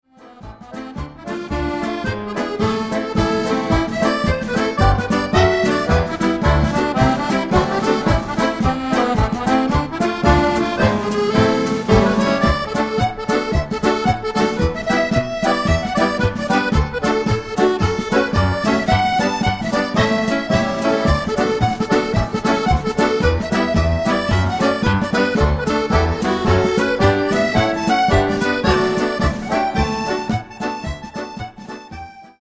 8 x 32 Jig